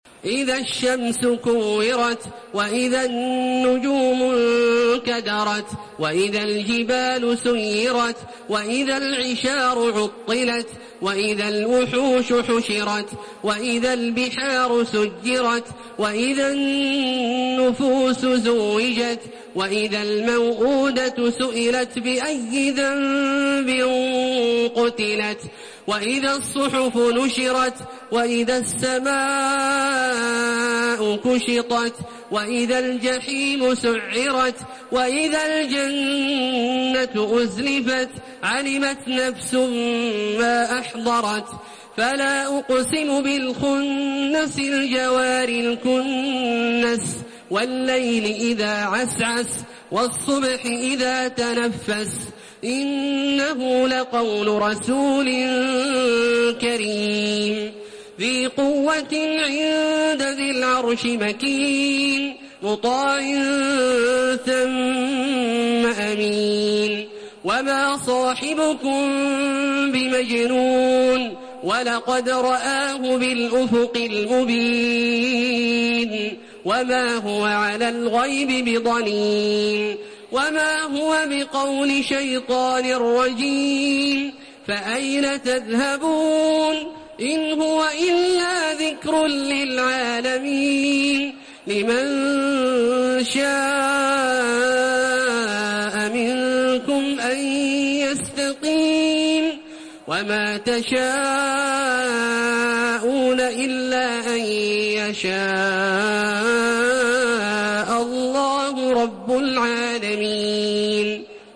Surah আত-তাকভীর MP3 by Makkah Taraweeh 1432 in Hafs An Asim narration.
Murattal